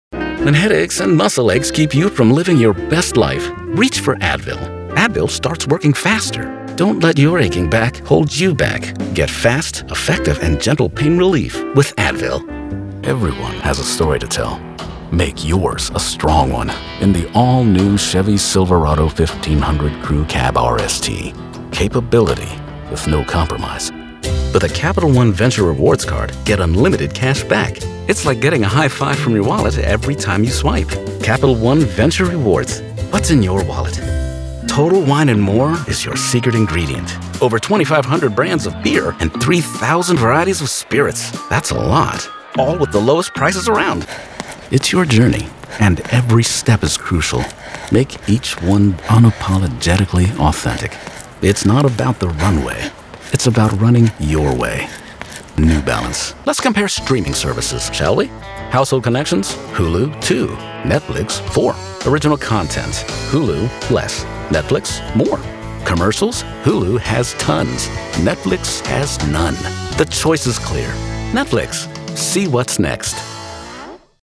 Narrator with a strong, confident and friendly voice to tell your story.
Commercial Demo